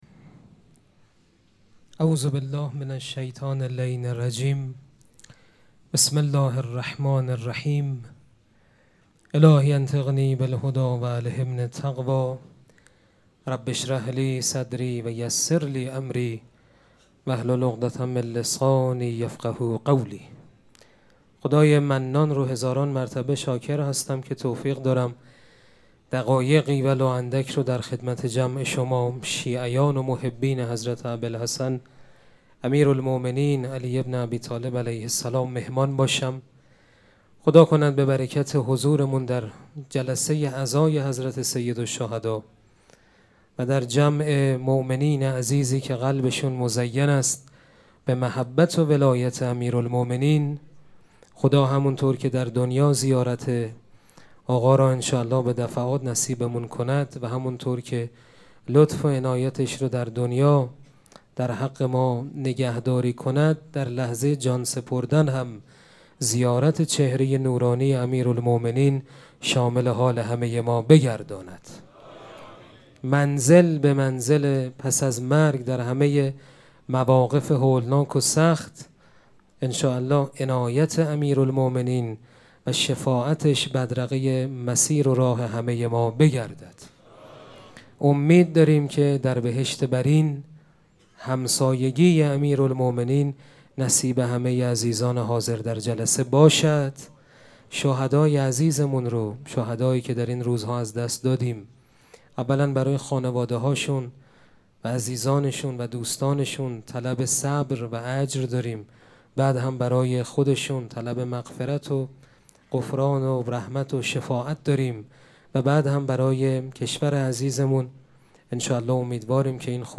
سخنرانی
مراسم عزاداری شب هفتم محرم الحرام ۱۴۴۷ چهارشنبه ۱۱تیر۱۴۰۴ | ۶ محرم‌الحرام ۱۴۴۷ هیئت ریحانه الحسین سلام الله علیها